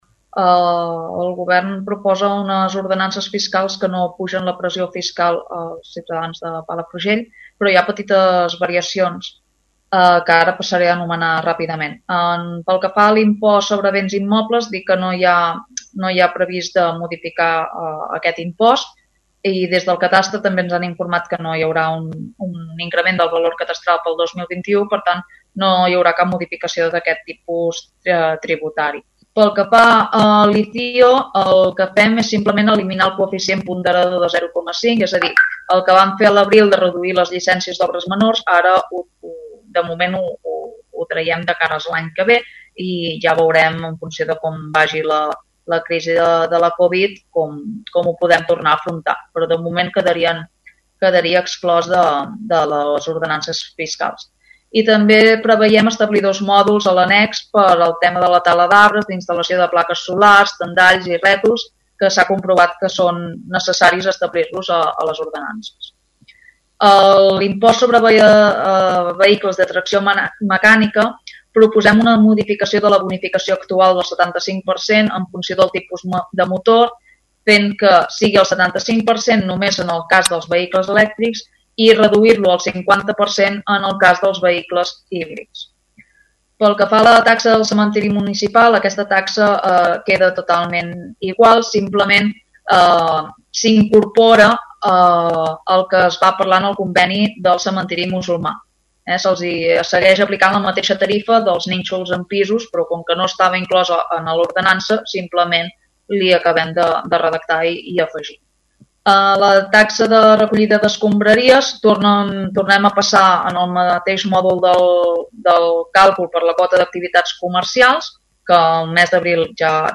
Ho explica millor Olga Palahí, regidora d’hisenda via ràdio Palafrugell.
La regidora també explica altres variacions a les ordenances fiscals de cara el 2021.